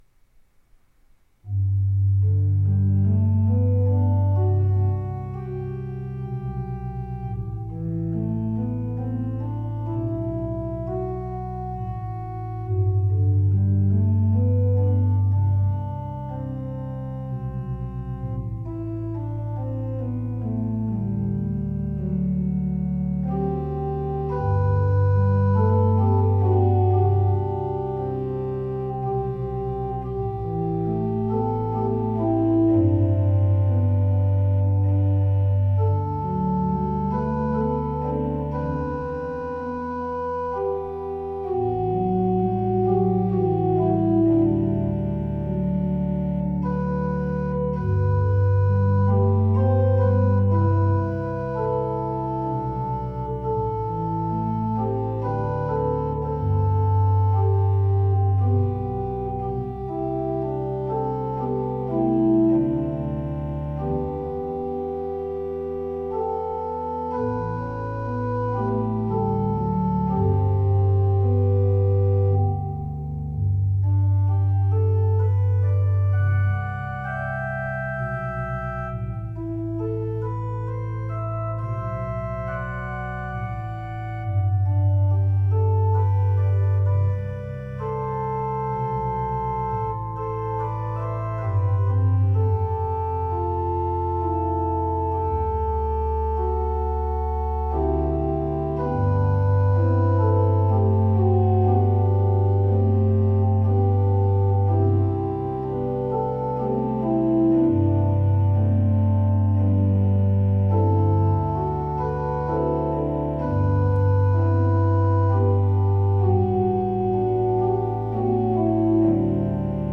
I Need Thee Every Hour (Need) – The Organ Is Praise
Even though this piece is simpler than most of my arrangements, I tried to keep it harmonically interesting.